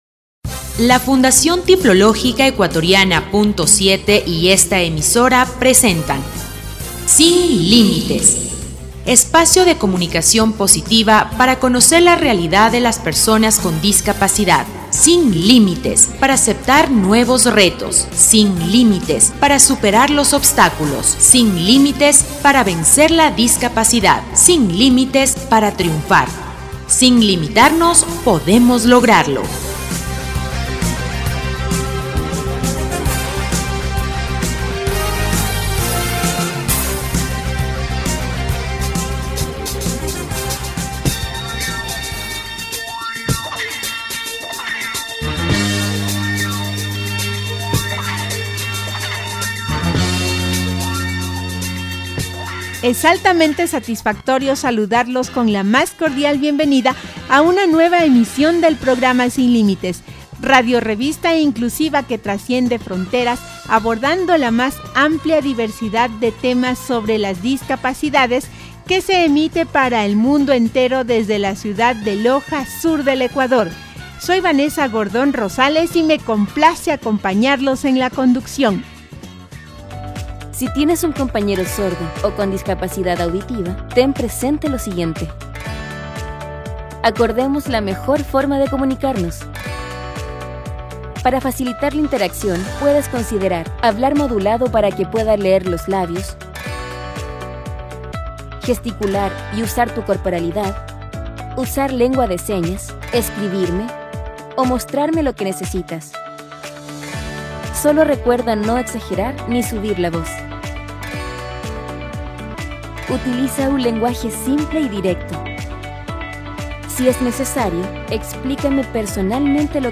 Programa radial «Sin Límites» 1325